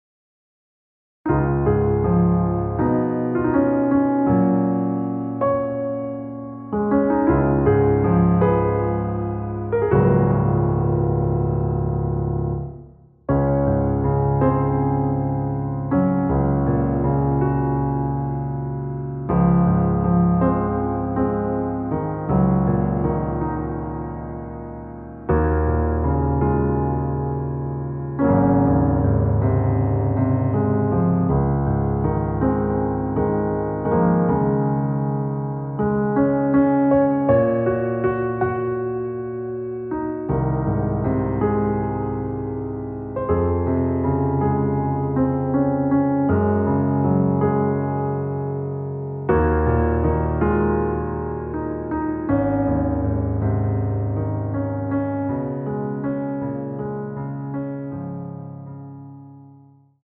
원키에서(-2)내린 MR입니다.
Db
앞부분30초, 뒷부분30초씩 편집해서 올려 드리고 있습니다.
중간에 음이 끈어지고 다시 나오는 이유는